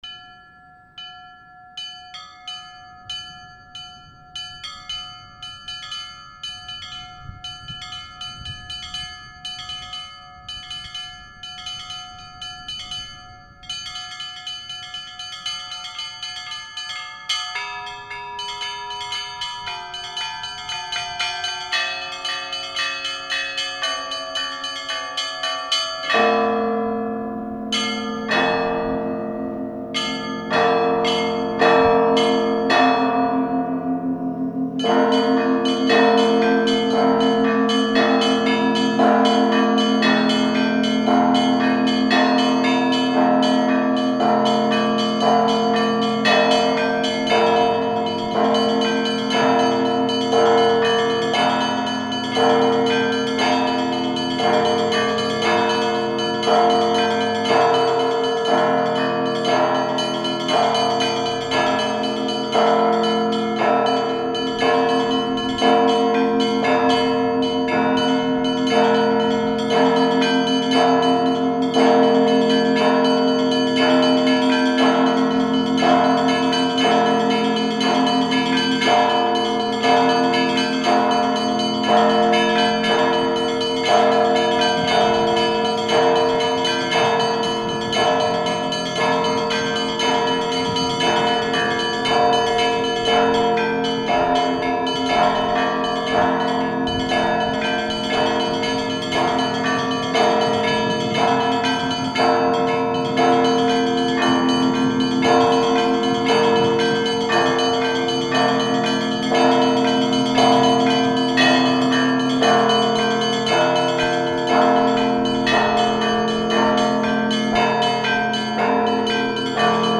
Звонница Спасо-Преображенского монастыря в Ярославле
Сейчас на ярусе звона находятся 18 старинных колоколов (XVI – начала XX в.) ярославского, псковского, петербургского и голландского литья. Самый большой колокол – благовестник весит около 2 тонн.
Красный звон